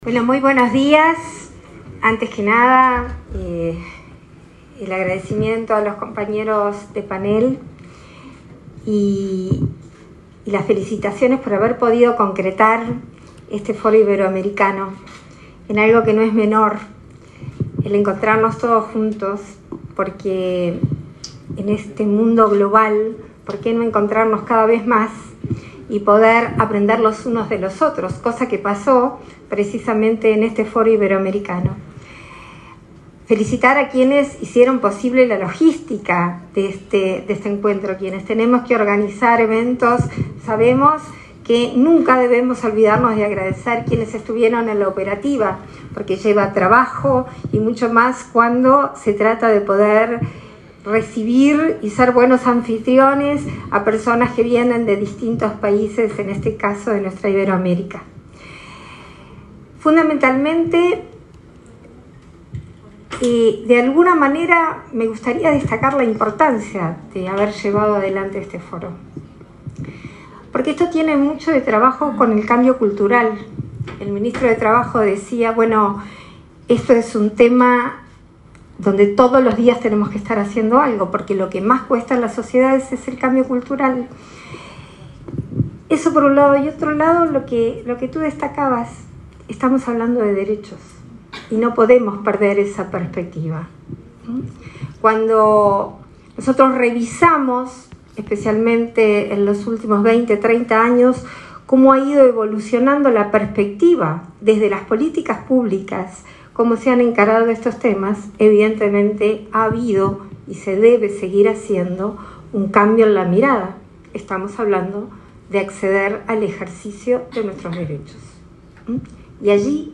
La presidenta en ejercicio, Beatriz Argimón, disertó este miércoles 26 en la clausura del VIII Foro Iberoamérica Incluye, realizado en Montevideo.